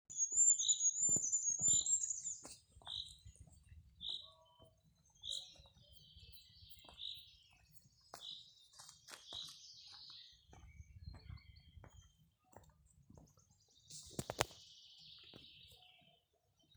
Goldcrest, Regulus regulus
StatusSinging male in breeding season
Notesbalss dzirdaama 1-3.sekundei.
Mežs, vietām sauss purvs. (Stompaku purva taka)